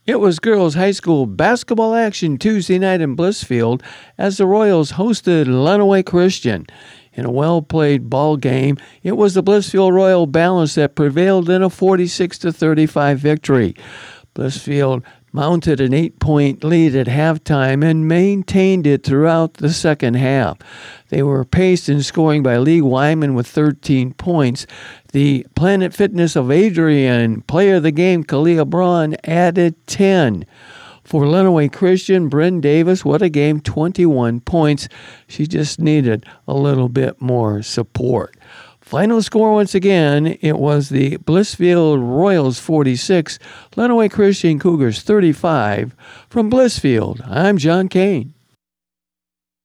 nws8521-aaa_sports_wrap.wav